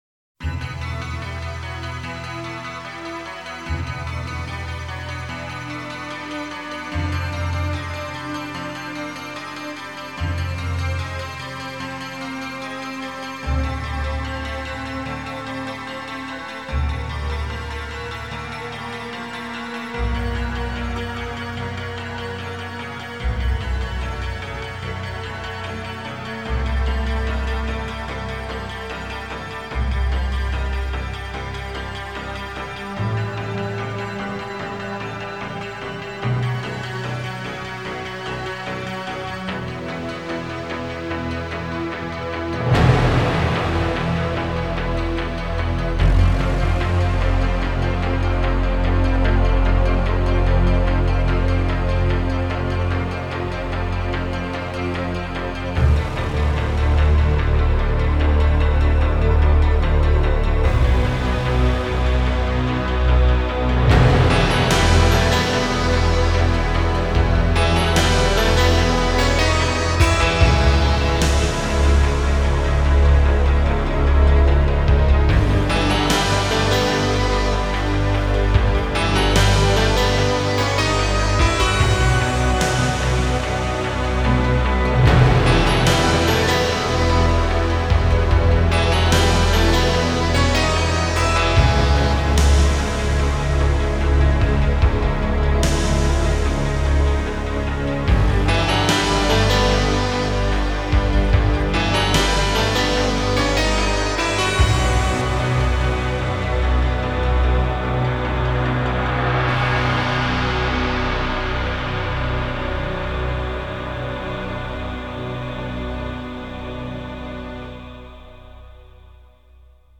Тип:Score